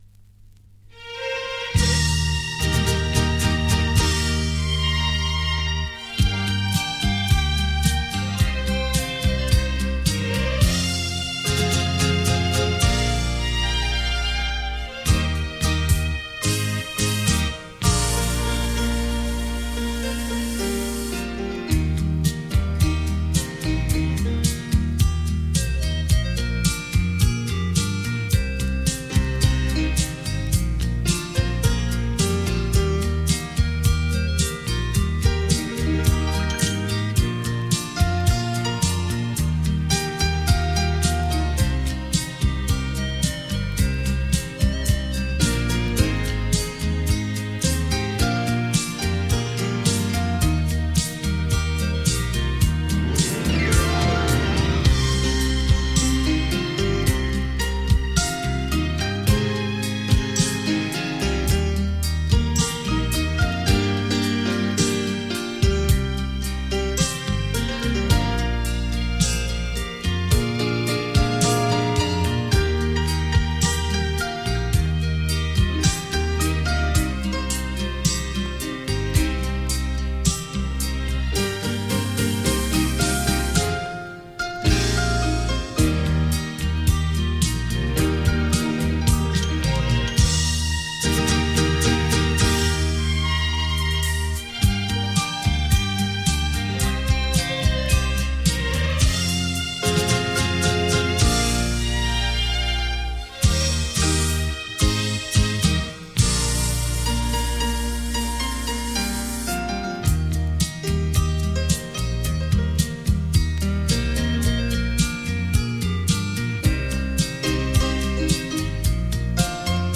令人回味的音韵 仿佛回到过往的悠悠岁月